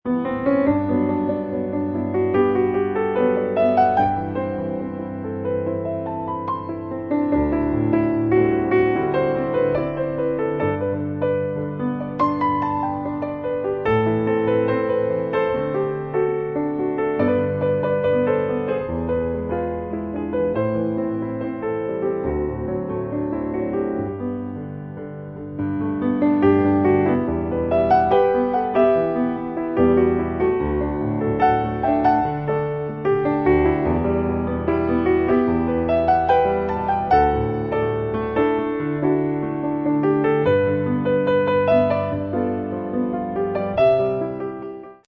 インストゥルメンタル
●ピアノ演奏
※歌は収録されていません。
心震えるほど美しく感動的なピアノで綴った珠玉の12曲を収録。